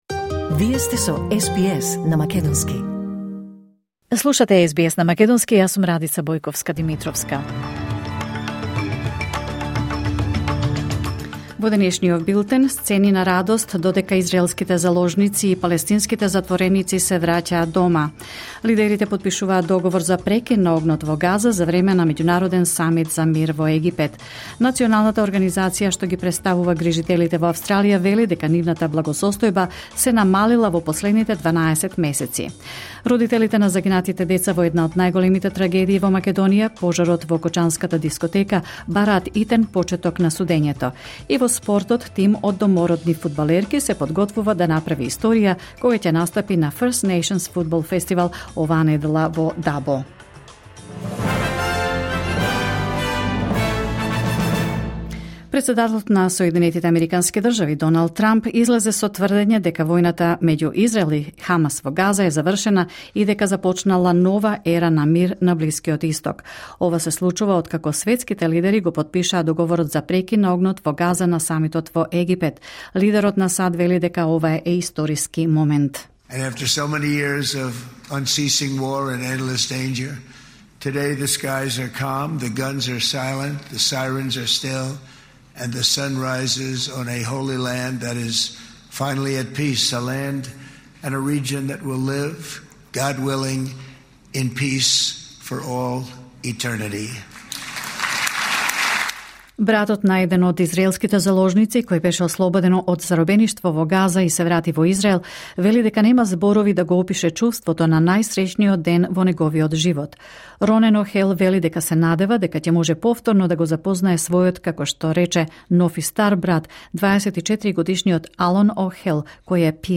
Вести